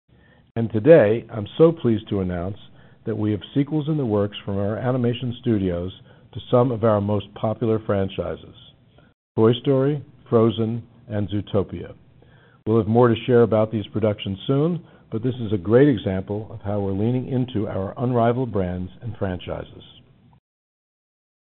During the February 2023 Disney Quarterly earnings call, CEO Bob Iger surprised fans by announcing that a new Toy Story film (Toy Story 5) was in development at Pixar.
Listen to a brief clip of Bob Iger announcing Toy Story 5 along with sequels for Frozen and Zootopia.